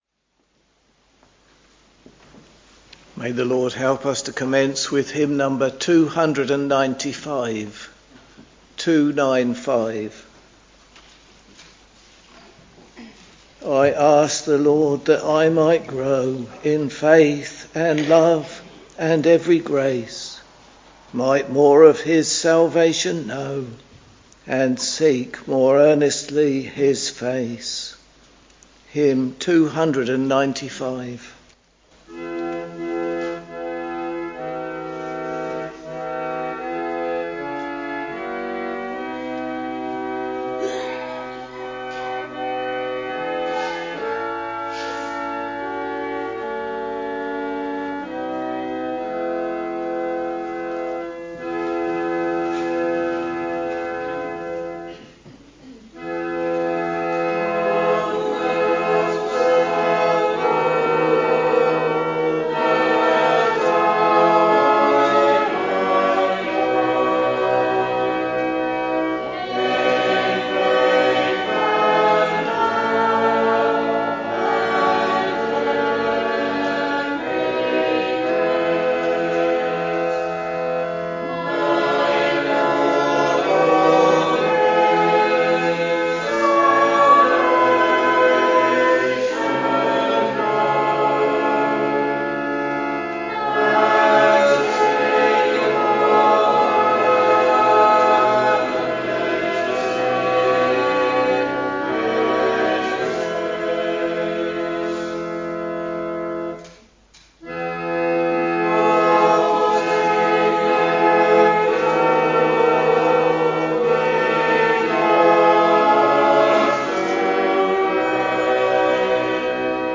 Evening Service Preacher
Hymns: 295, 422, 746 Reading: John 5 Bible and hymn book details Listen Download File